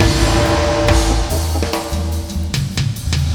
FILLBIG 01-R.wav